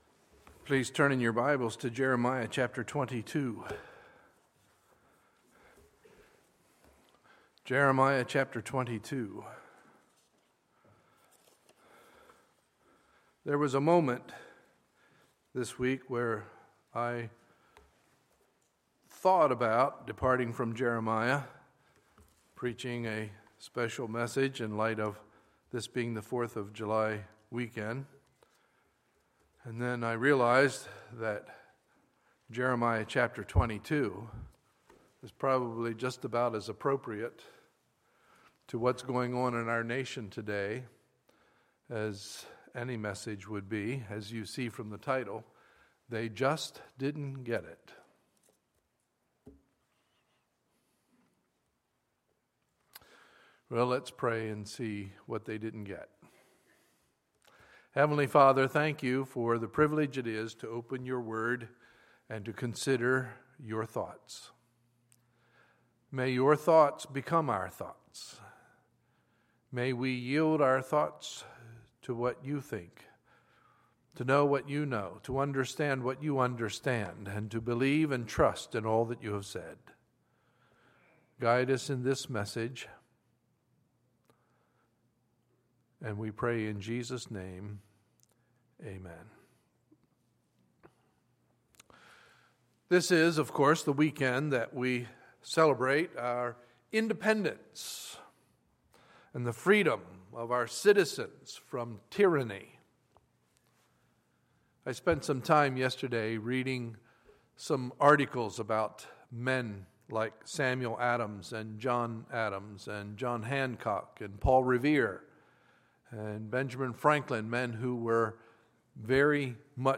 Sunday, July 5, 2015 – Sunday Morning Service